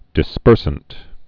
(dĭ-spûrsənt)